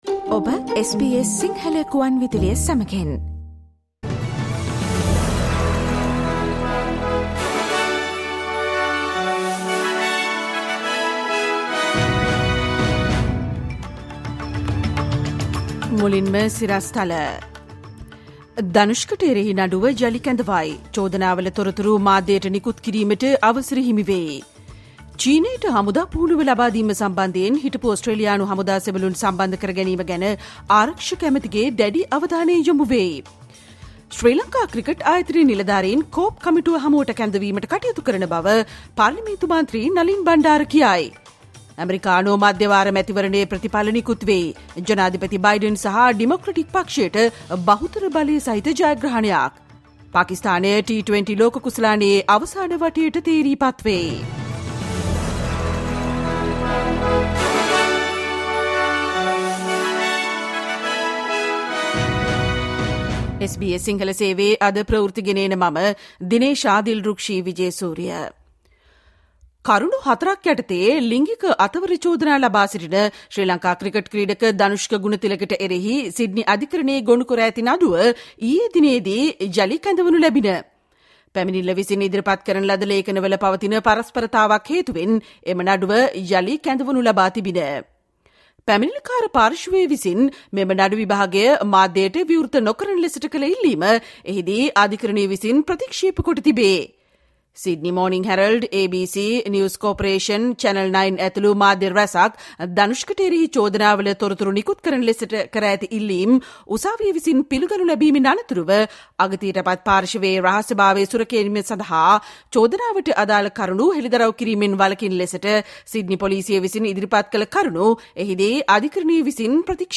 Listen to the SBS Sinhala Radio news bulletin on Thursday 10 November 2022